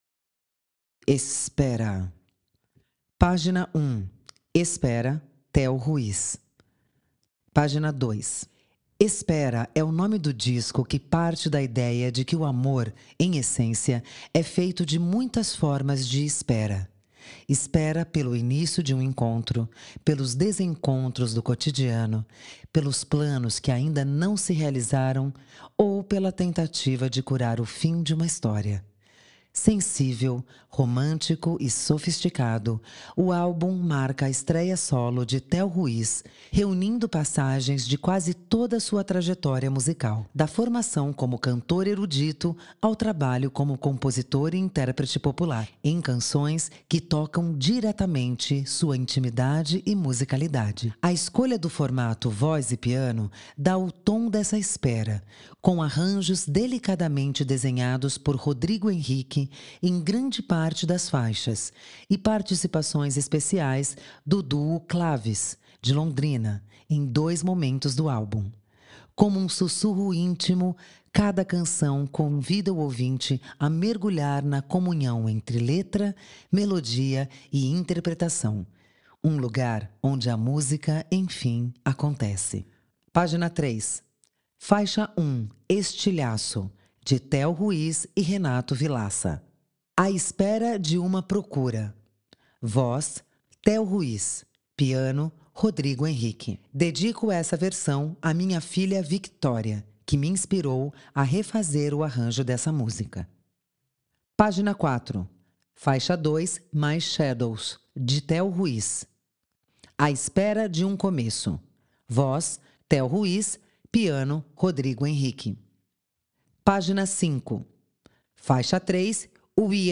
CONFIRA O ENCARTE Audiodescrição Espera –…